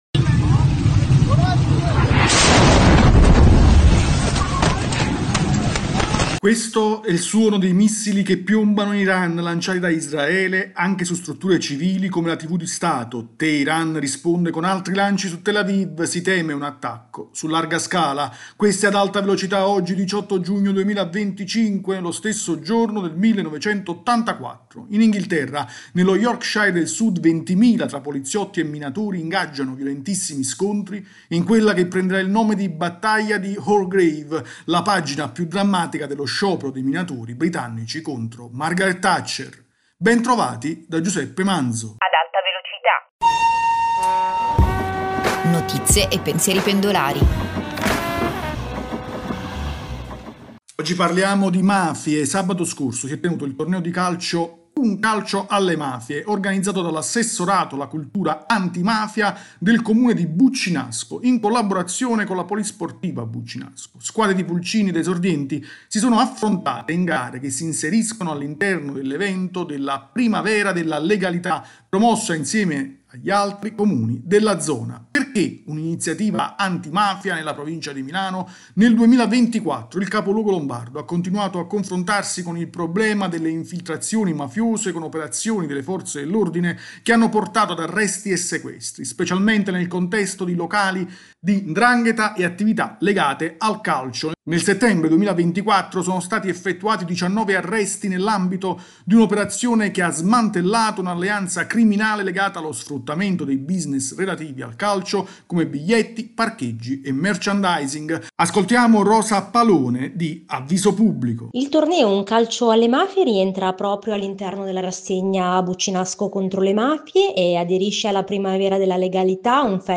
[Intro: Questo è il suono dei missili che piombano in Iran lanciati da Israele anche su strutture civili come la tv di Stato, Teheran risponde con altri lanci su Tel Aviv: si teme un attacco su larga scala.
Ascoltiamo Rosa Palone, vicesindaco di Buccinasco e Avviso Pubblico.